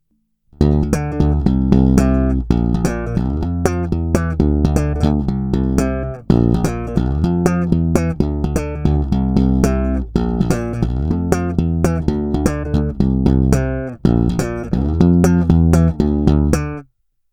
Nahrávky jsou provedeny rovnou do zvukovky a dále kromě normalizace ponechány bez úprav.
Na 2EQ 93 jsem dal basy skoro naplno a výšky pocitově někde kolem střední polohy.
2EQ 93 – Slap